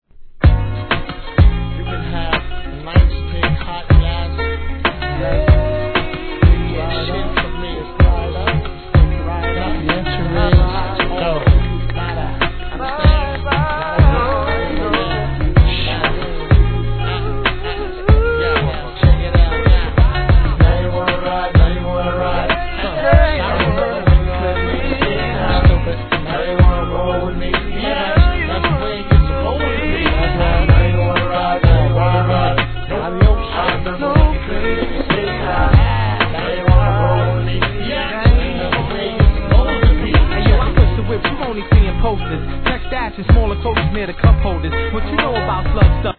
HIP HOP/R&B
ギターの気持ちい〜いトラックに